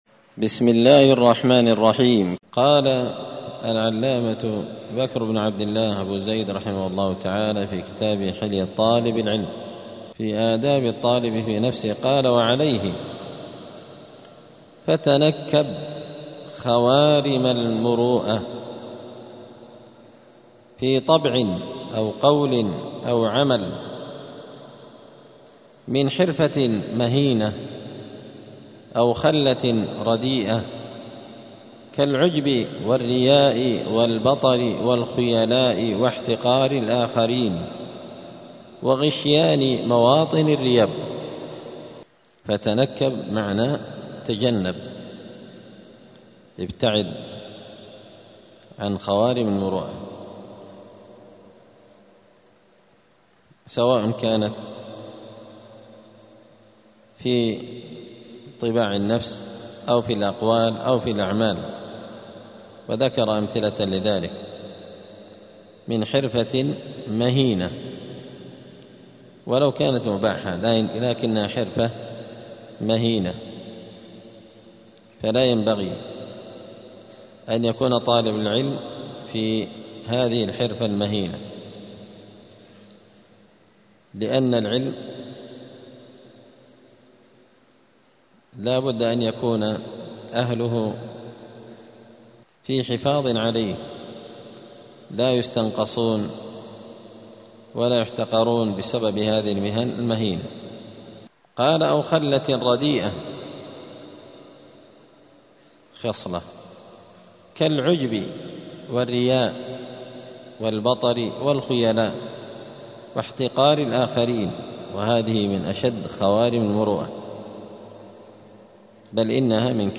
دار الحديث السلفية بمسجد الفرقان قشن المهرة اليمن 📌الدروس الأسبوعية